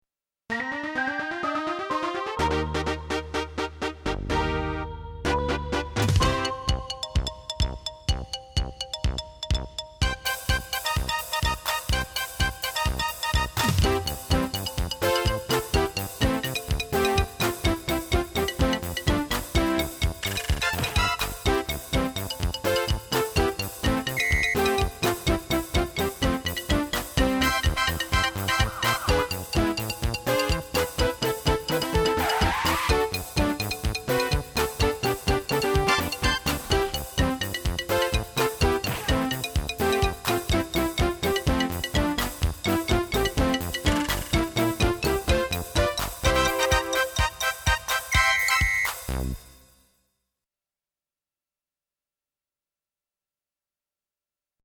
Extra soundmixbegeleidingen voor tutors instrument: Oefening 1 -